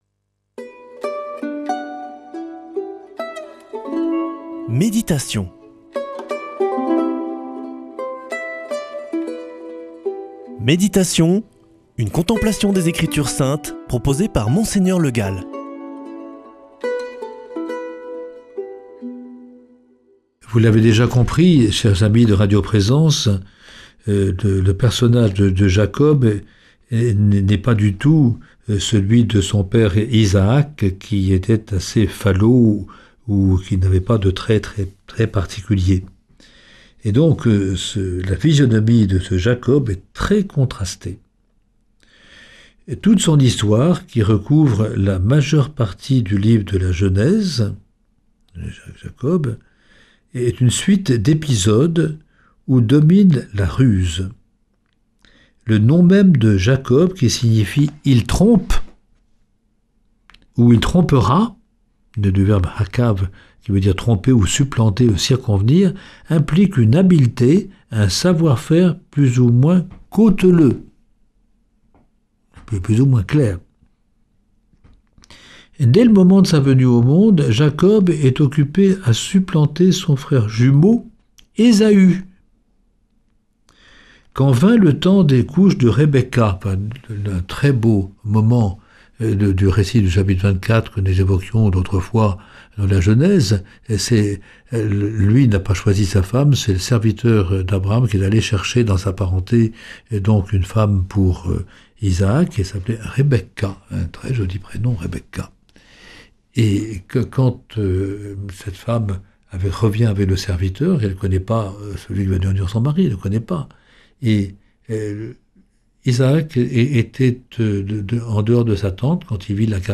Méditation avec Mgr Le Gall
Monseigneur Le Gall
Présentateur